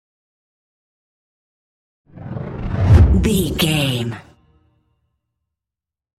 Whoosh deep fast
Sound Effects
Fast
dark
intense
whoosh